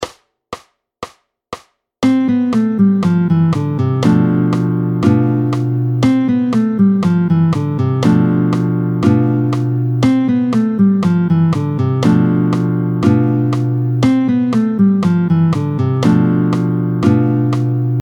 20-01 Gamme de Do majeur et accord Sim7/5b. Vite tempo 120